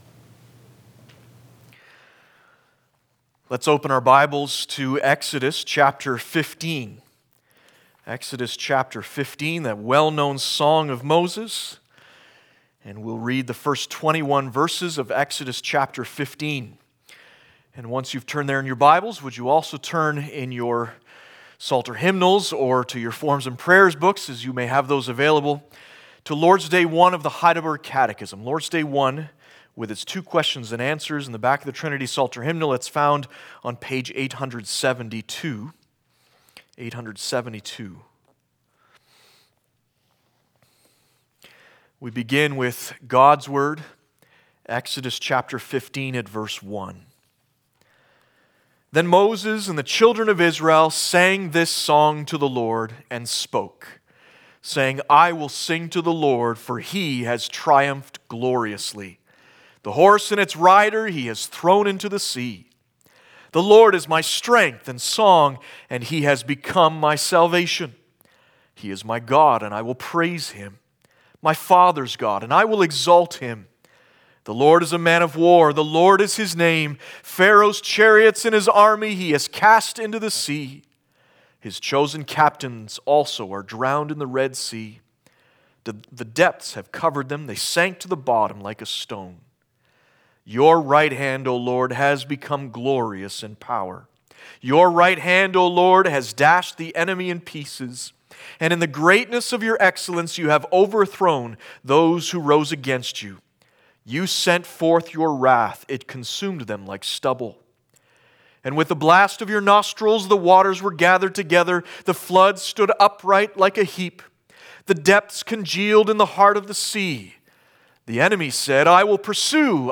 Passage: Exodus 15:1-21 Service Type: Sunday Afternoon